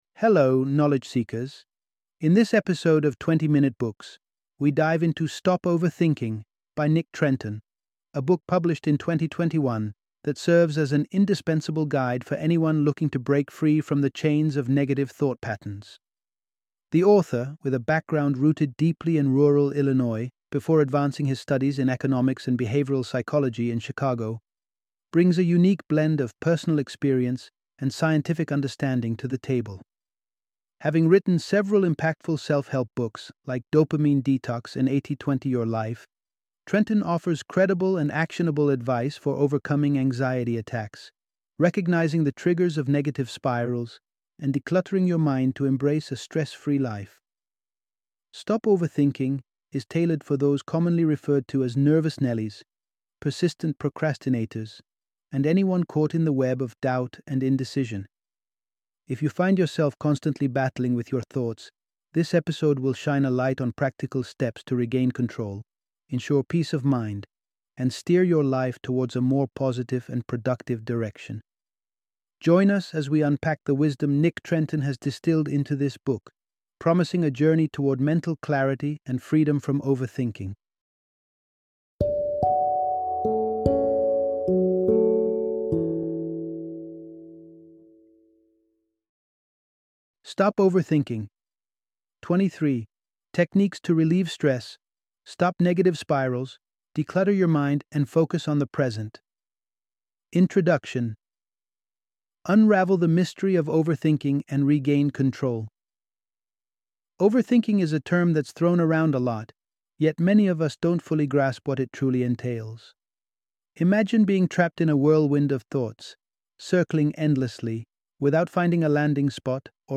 Stop Overthinking - Audiobook Summary